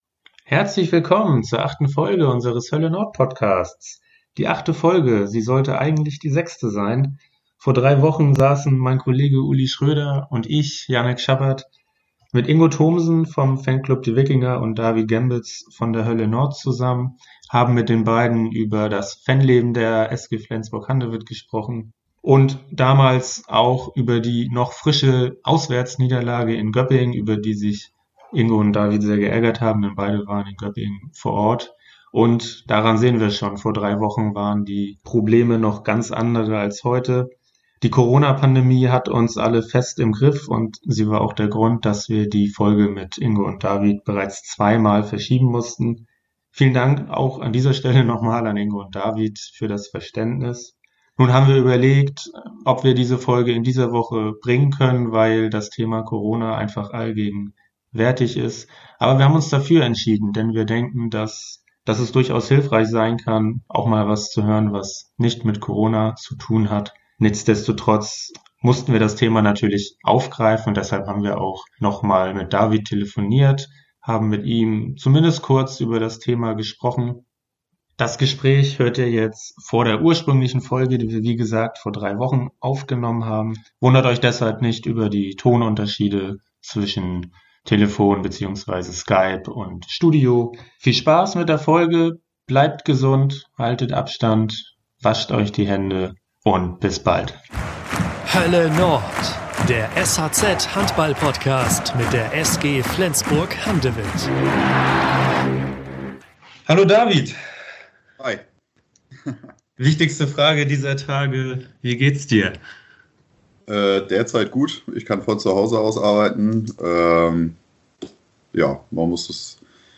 Für die Aufnahme der sechsten Folge des "Hölle Nord"-Podcasts kamen die beiden Fans ins sh:z-Podcast-Studio.